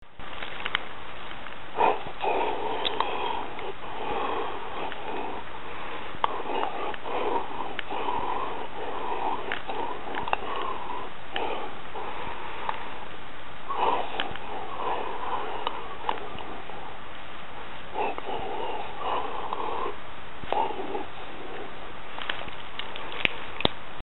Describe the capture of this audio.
Â The constant air flow in and out because of breathing is modulated by your vocal cords in silent speaking when you think and it is the recording of this modulation that creates ‘speak thinking’.